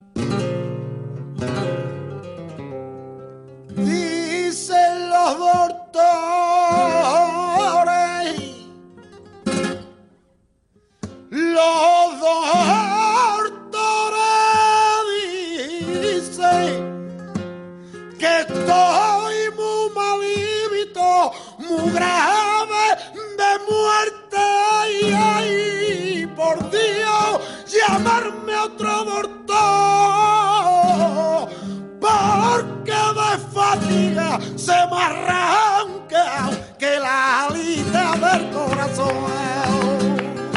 Tientos (otros)